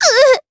Dosarta-Vox_Damage_jp_01.wav